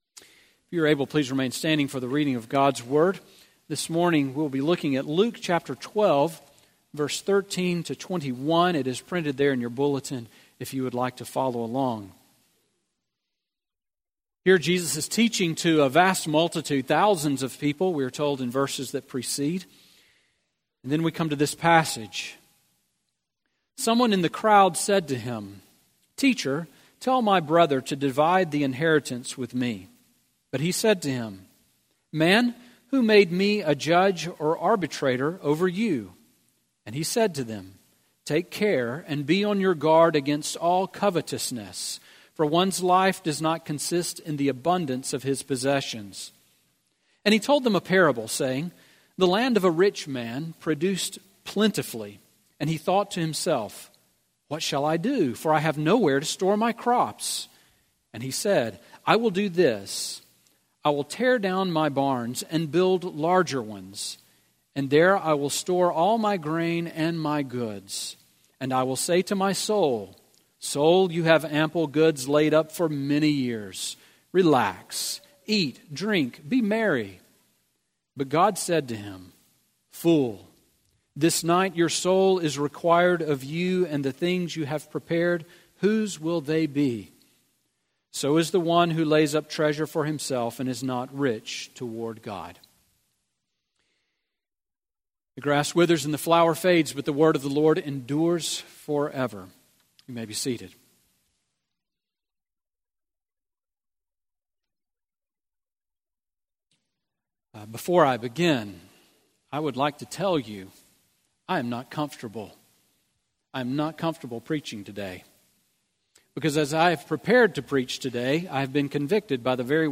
Sermon on Luke 12:13-21 from November 19